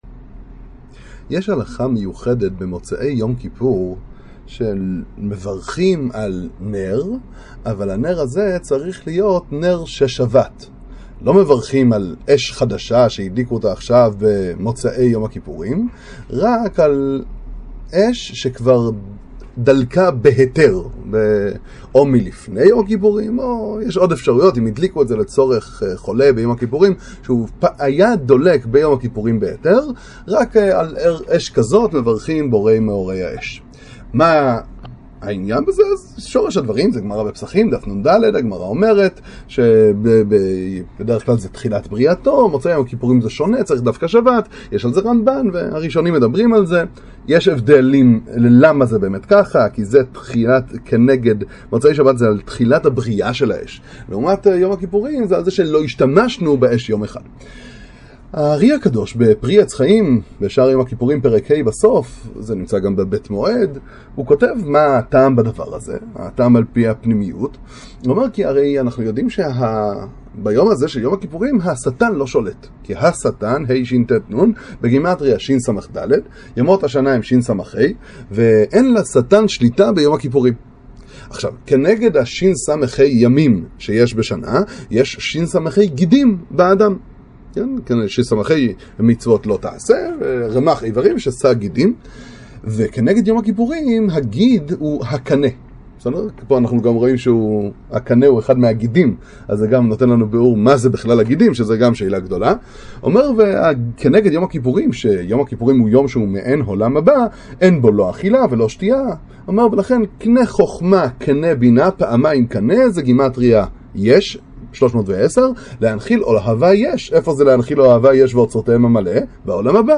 דבר תורה על פרשת השבוע